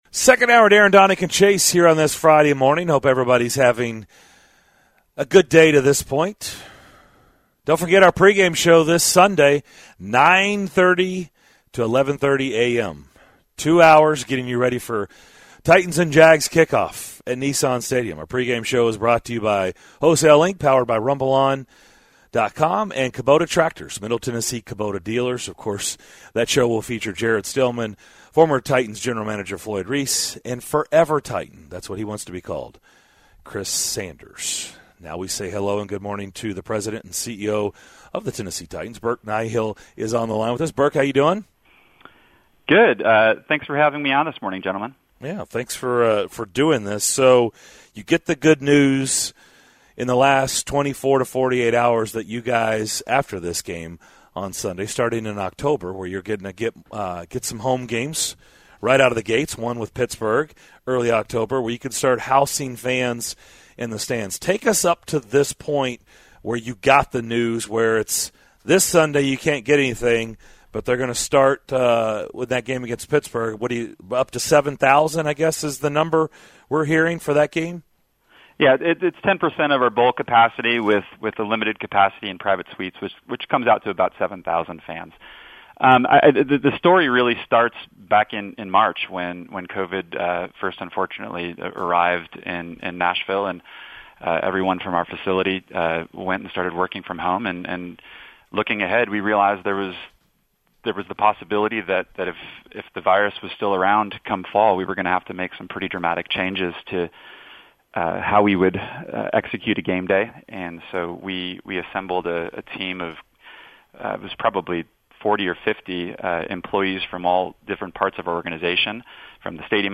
talk with callers about the future of the stadium